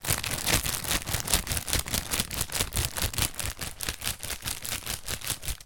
descargar sonido mp3 arrugar
crackle-crackle.mp3